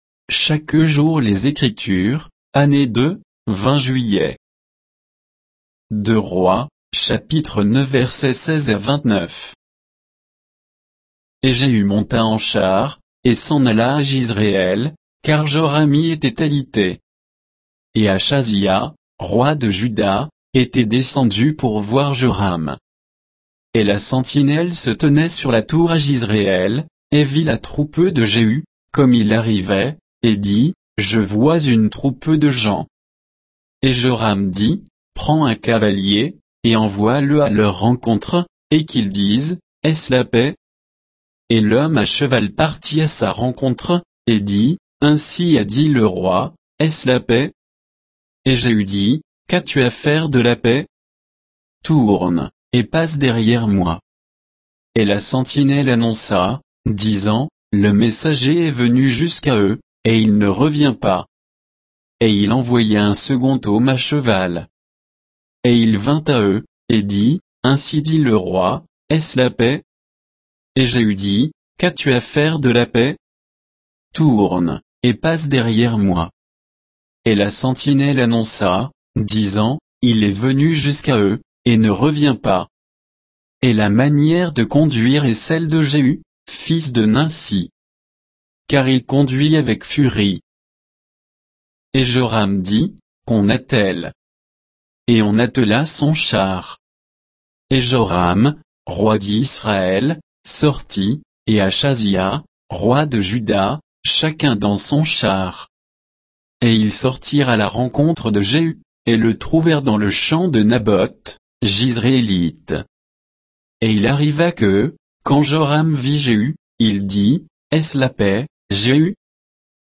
Méditation quoditienne de Chaque jour les Écritures sur 2 Rois 9